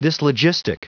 Prononciation du mot dyslogistic en anglais (fichier audio)
dyslogistic.wav